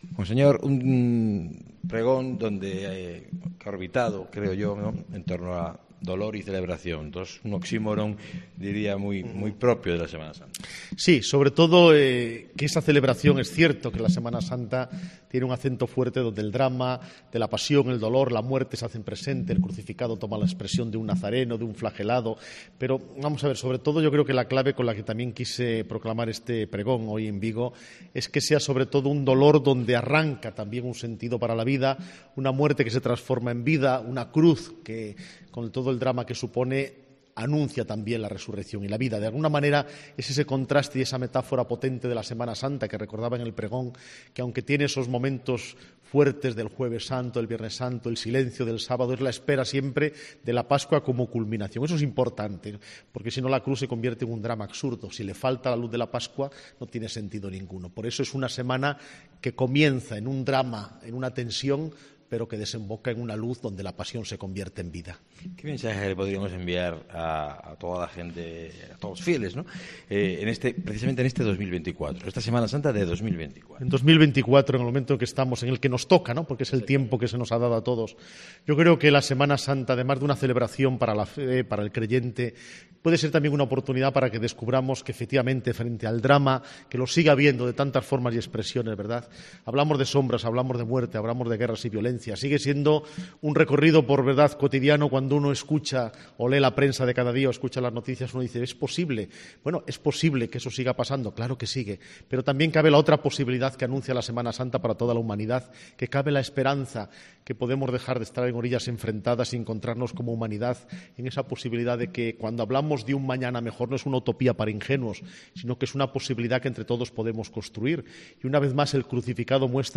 Entrevista con el Arzobispo de Santiago, Monseñor Francisco José Prieto
El pregón del Arzobispo de Santiago fue un canto de esperanza y celebración de la vida. Tras su intervención atendió al micrófono de la Cadena COPE.